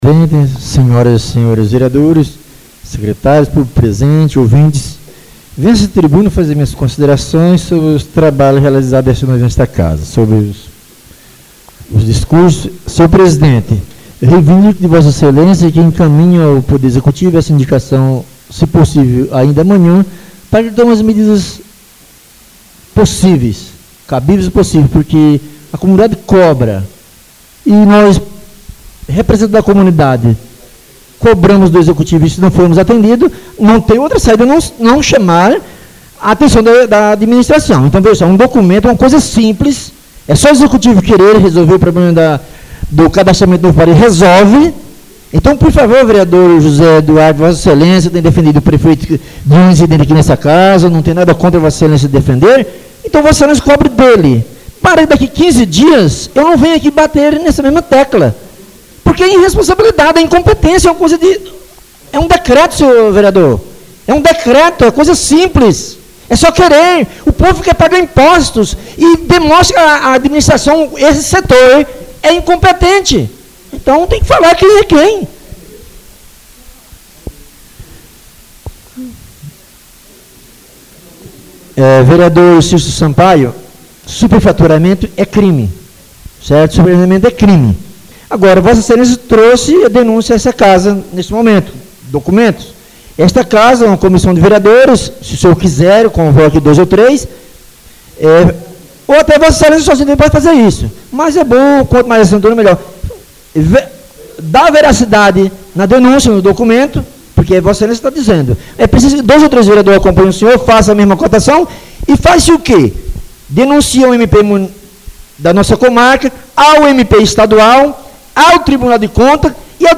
Oradores das Explicações Pessoais (25ª Ordinária da 3ª Sessão Legislativa da 6ª Legislatura)